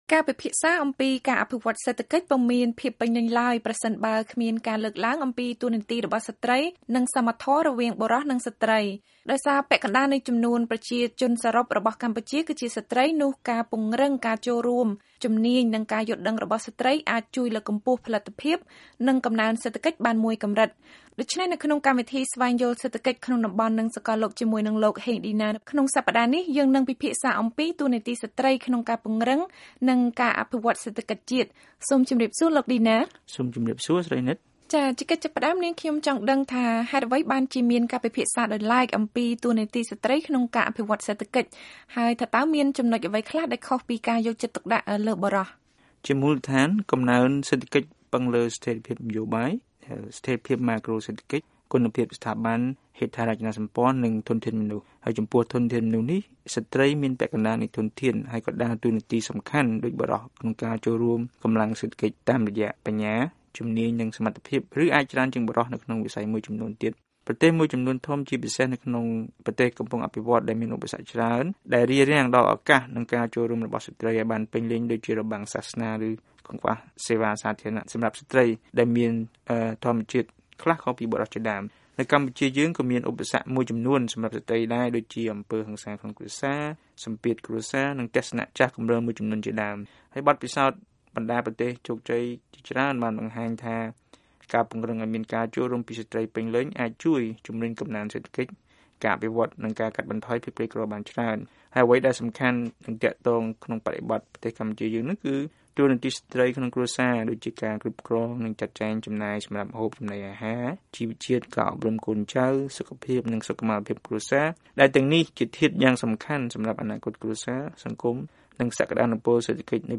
បទសម្ភាសន៍សេដ្ឋកិច្ច៖ ស្ត្រី និងការអភិវឌ្ឍន៍សេដ្ឋកិច្ច